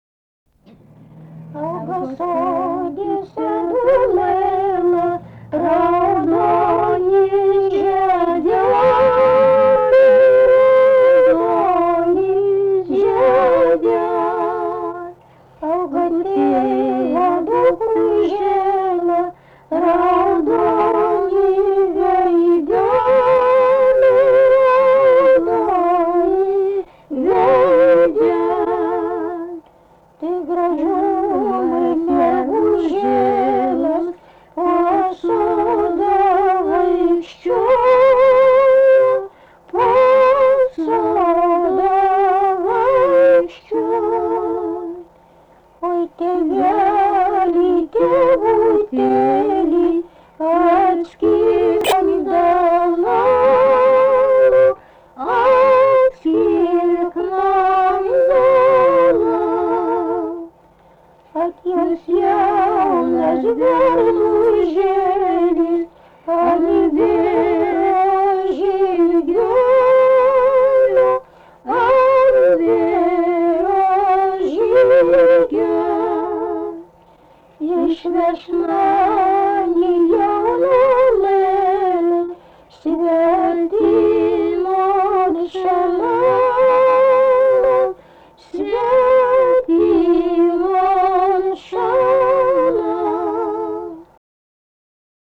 daina, vaikų